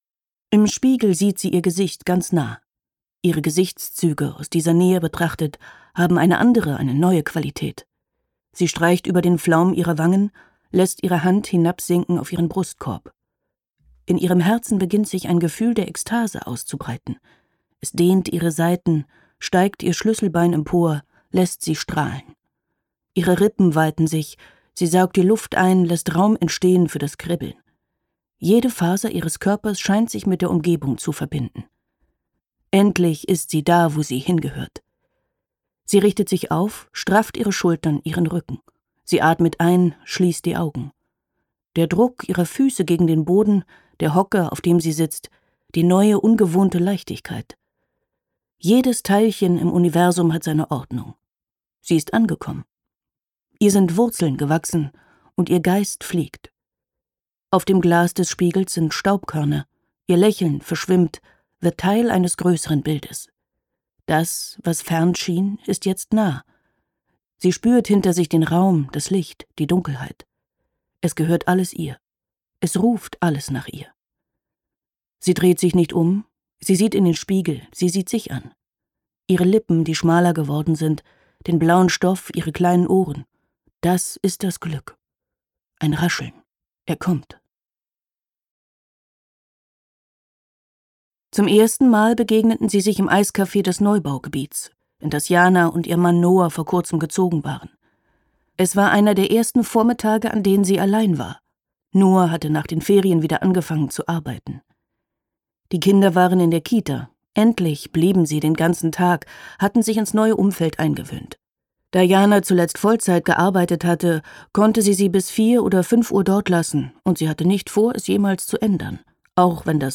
Heimat - Hannah Lühmann | argon hörbuch
Gekürzt Autorisierte, d.h. von Autor:innen und / oder Verlagen freigegebene, bearbeitete Fassung.